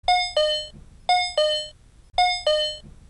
Звуки дверей автомобиля
Звук сигнала открытой двери ВАЗ 2110-12, пищалка бортового компьютера